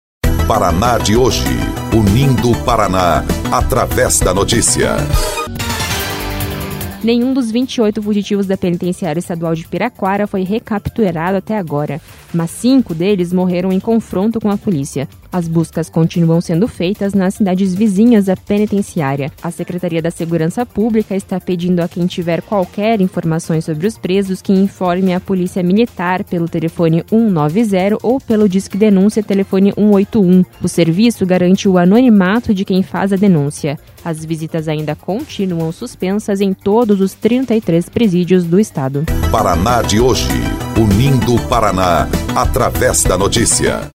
BOLETIM - 28 detentos da Penitenciária Estadual de Piraquara continuam foragidos